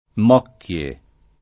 [ m 0 kk L e ]